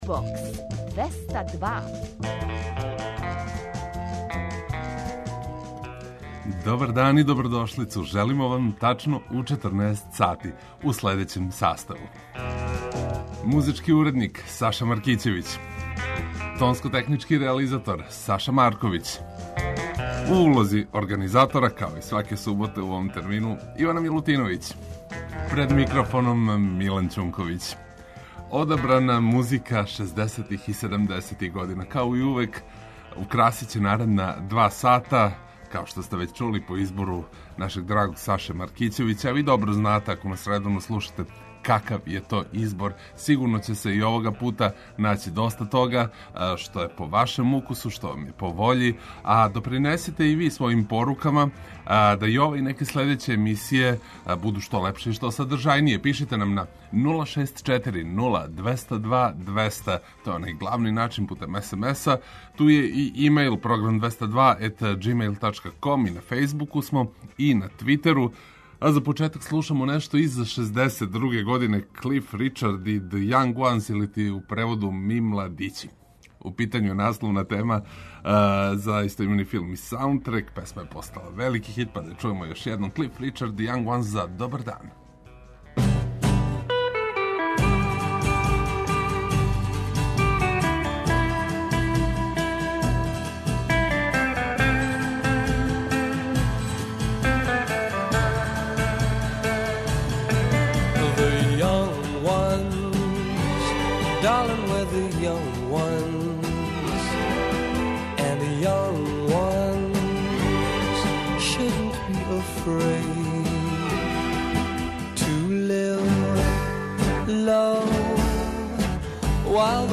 преузми : 55.28 MB Џубокс 202 Autor: Београд 202 Уживајте у пажљиво одабраној старој, страној и домаћој музици.